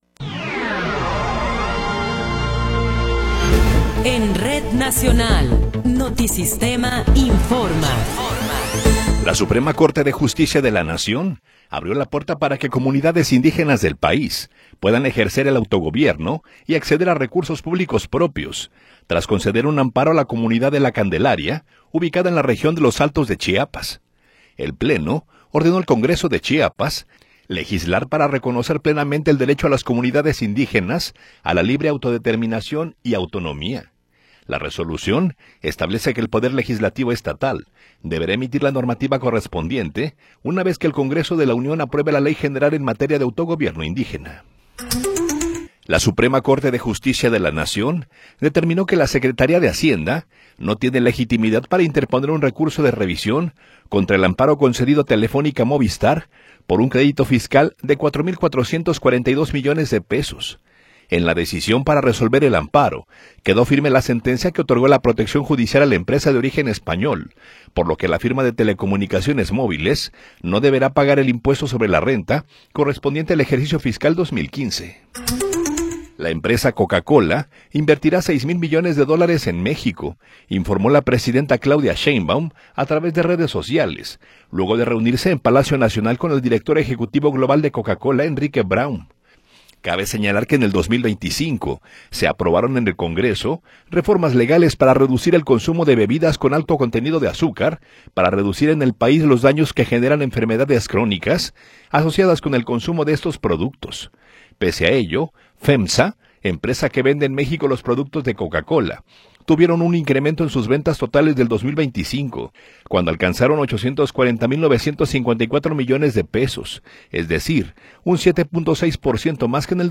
Noticiero 17 hrs. – 26 de Febrero de 2026